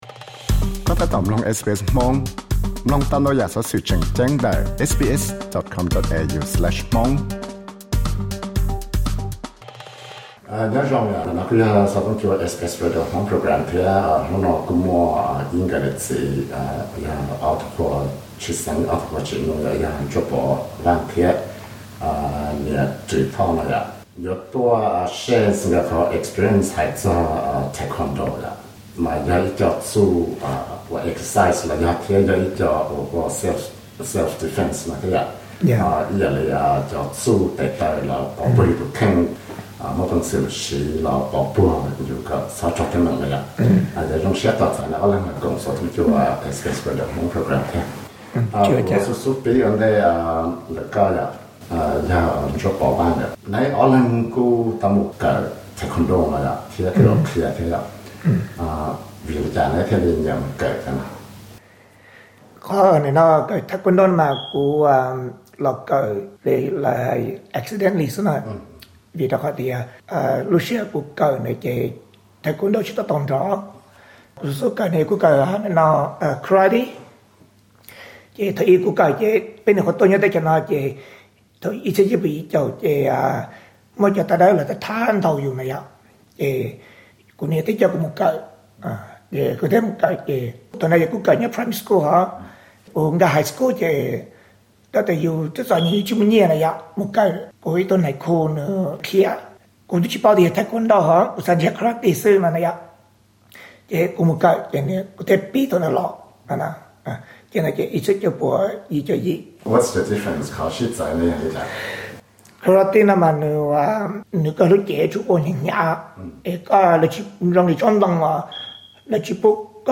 Interview: Taekwondo tej txiaj ntsim